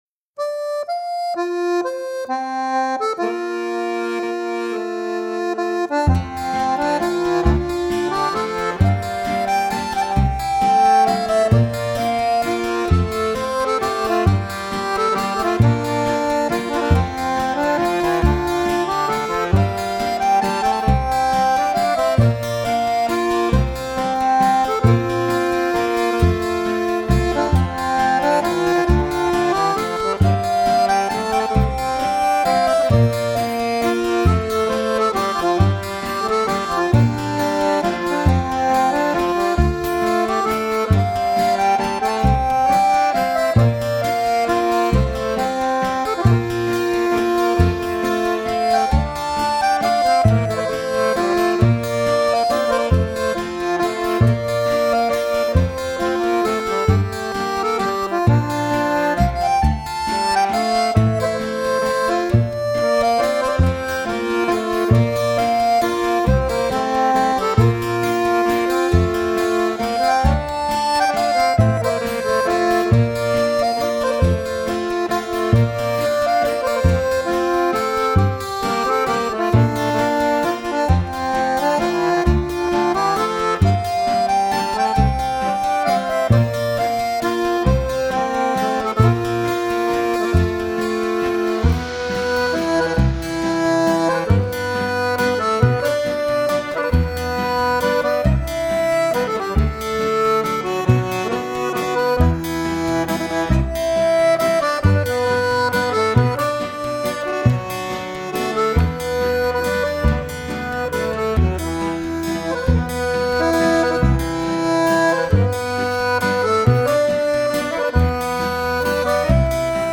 Accordion
Drums and Guitar